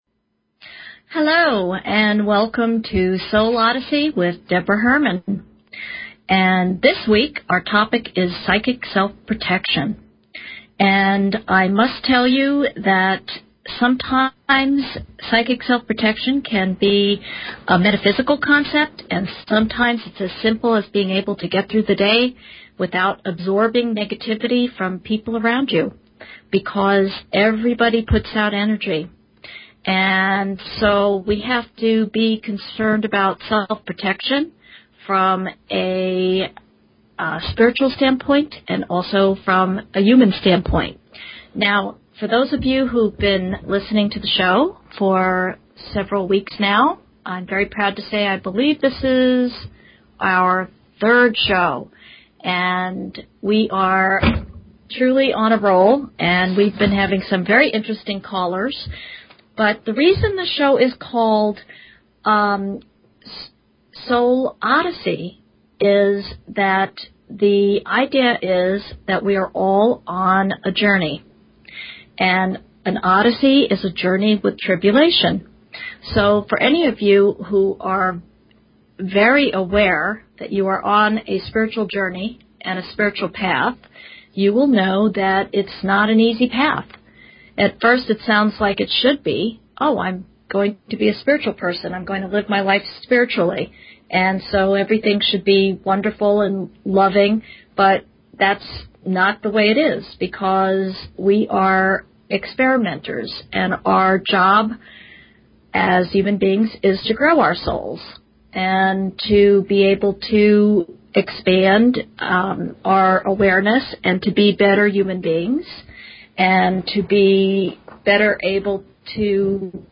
Talk Show Episode, Audio Podcast, Soul_Odyssey and Courtesy of BBS Radio on , show guests , about , categorized as
live call in.